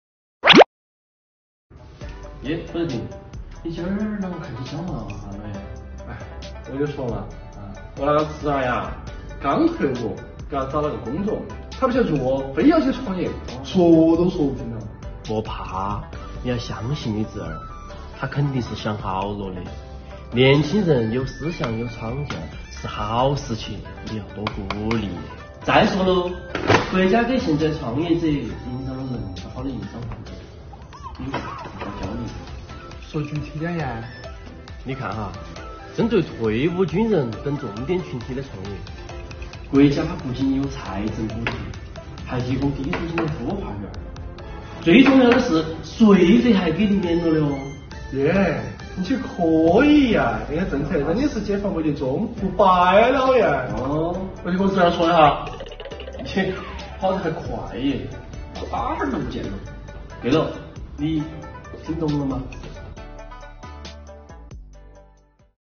重庆言子来了③ | 这类人群的创业就业税费优惠政策您知道吗？